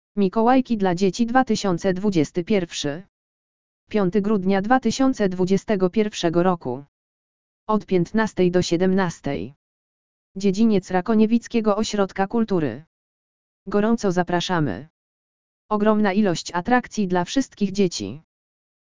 audio_lektor_mikolajki_dla_dzieci_2021.mp3